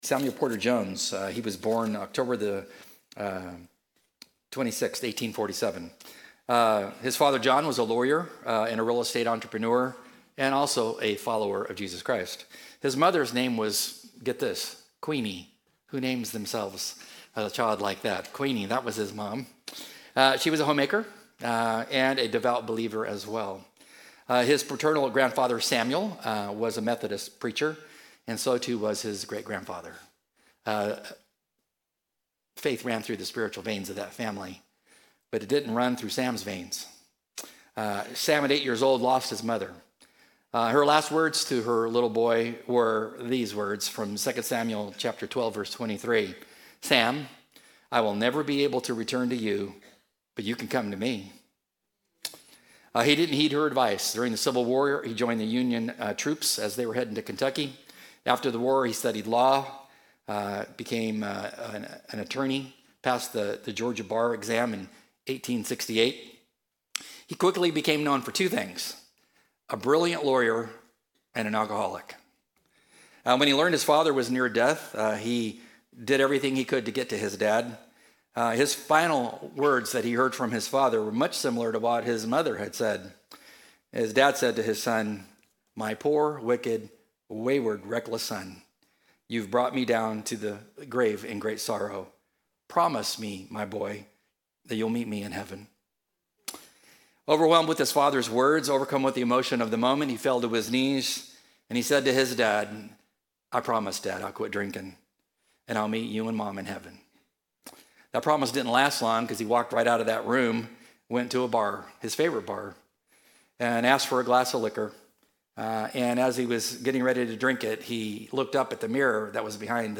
Our Good Friday service is a time of somber reflection and intimate worship.